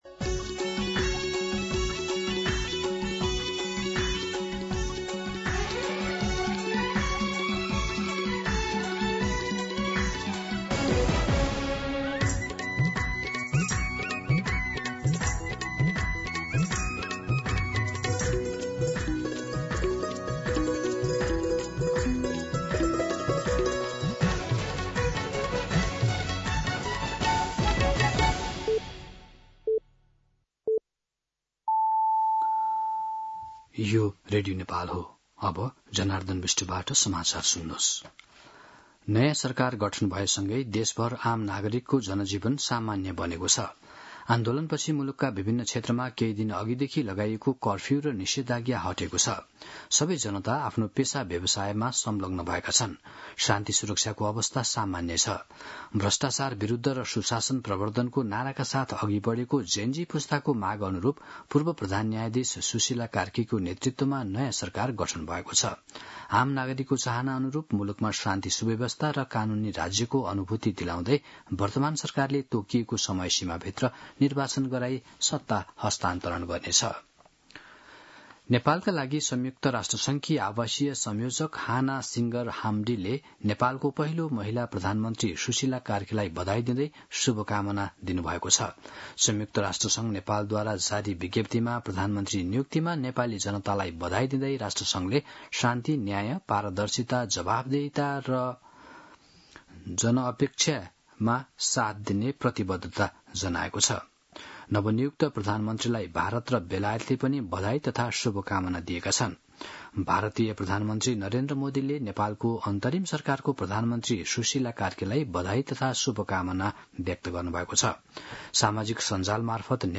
दिउँसो १ बजेको नेपाली समाचार : २८ भदौ , २०८२
1-pm-Nepali-News.mp3